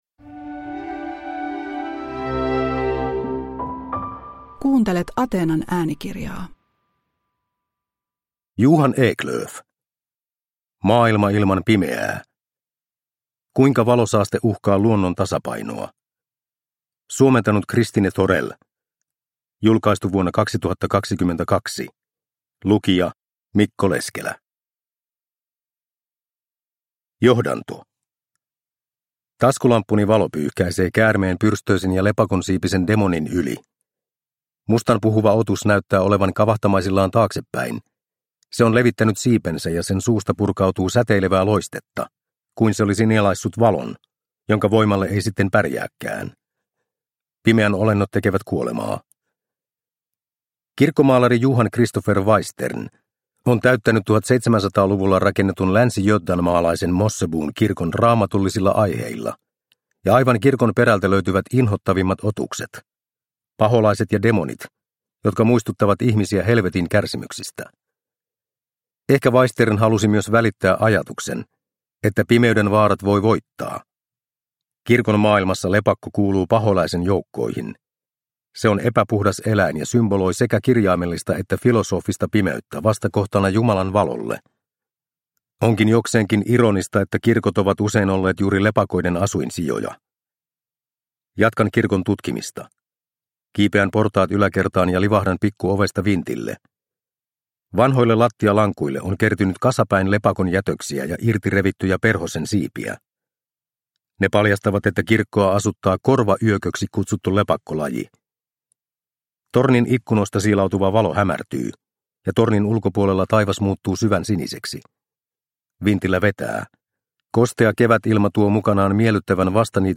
Maailma ilman pimeää – Ljudbok – Laddas ner